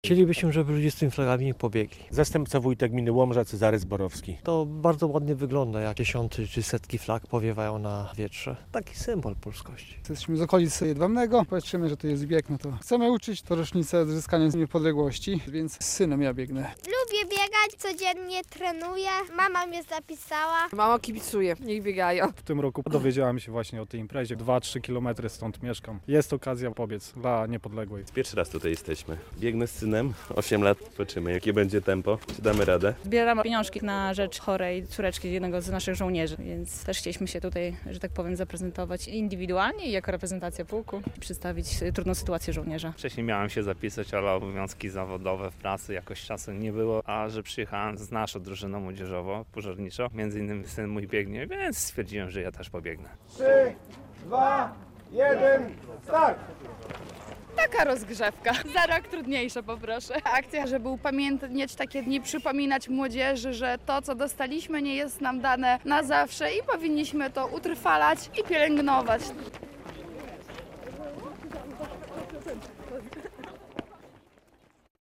Bieg z biało-czerwoną flagą - tak mieszkańcy gminy Łomża upamiętnili Narodowe Święto Niepodległości - relacja